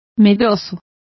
Complete with pronunciation of the translation of fearful.